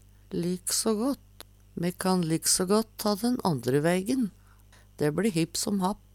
DIALEKTORD PÅ NORMERT NORSK lik so gått like gjerne Eksempel på bruk Me kan lik so gått gå den andre veigen.